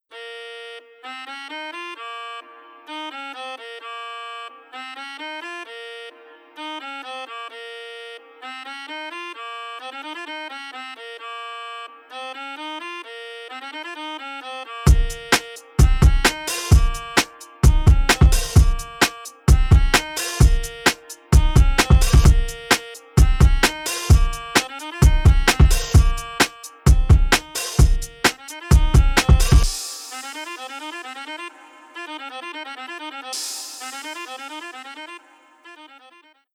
包络、按键噪声和风量。